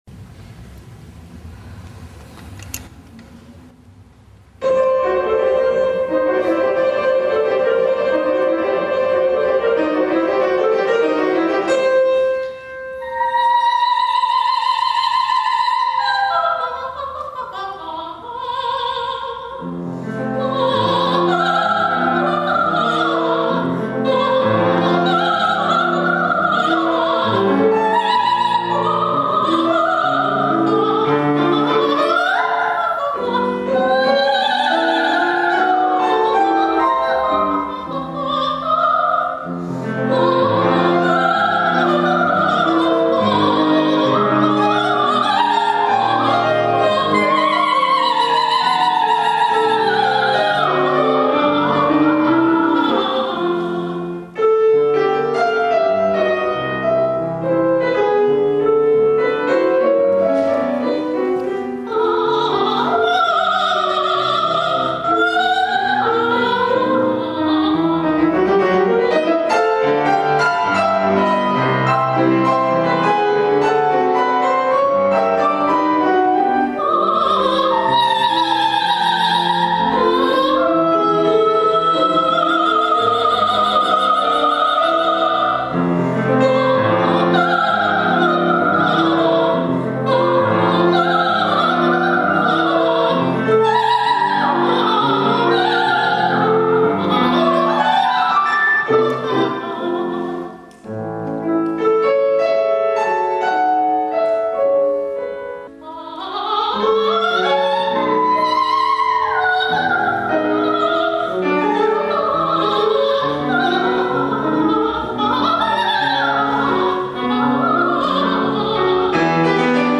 Архив концертных выступлений
Р.Глиэр. Концерт для колоратурного сопрано. 2ч.